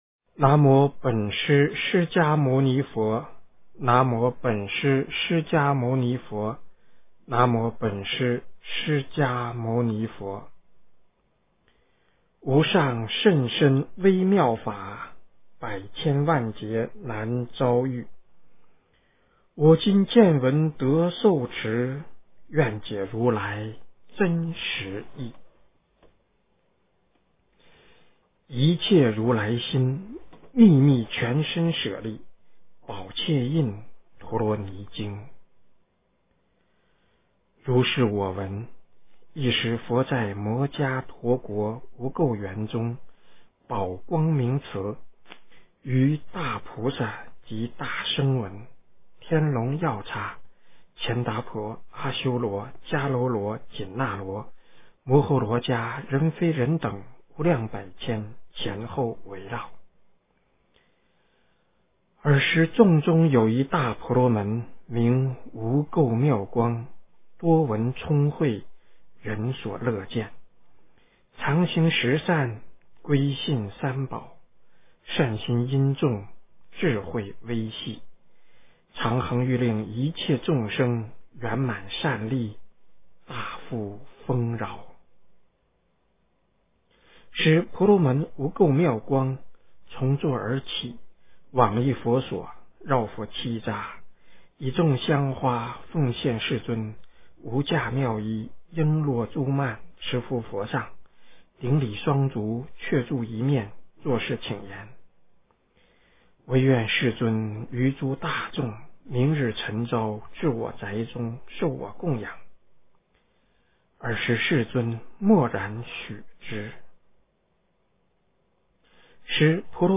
一切如来心秘密全身舍利宝箧印陀罗尼经 - 诵经 - 云佛论坛